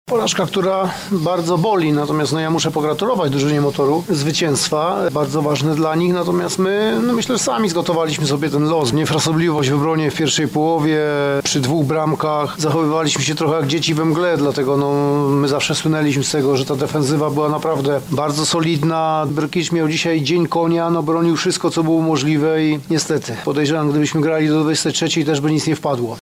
• mówił po spotkaniu trener Korony Jacek Zieliński.